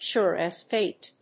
アクセント(as) súre as fáte